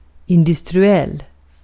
Uttal
Uttal US: IPA : [ɪn.ˈdəs.tri.əl] Okänd accent: IPA : /ɪnˈdʌstrɪəl/ Förkortningar ind.